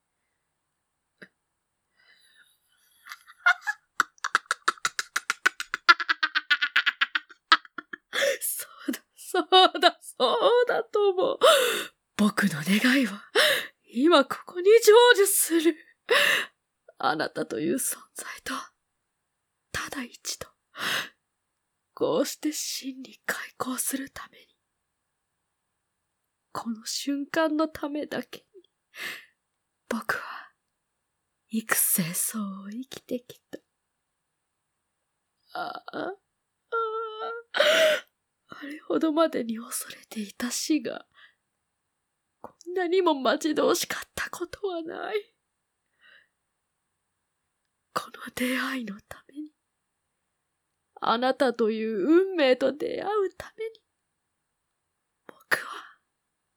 抑揚がない話し方や立ち振る舞い、